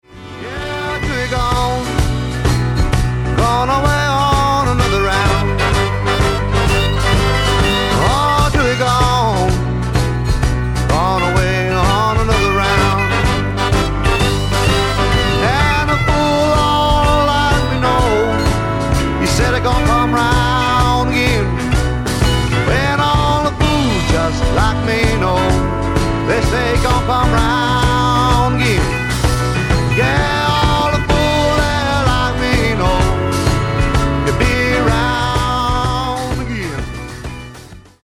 SSW / SWAMP ROCK